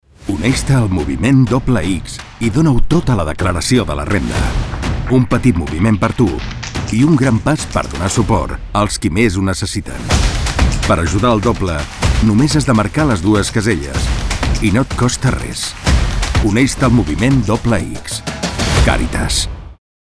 Us adjuntem els materials de la campanya : Anunci audiovisual Cartell Falca radiofònica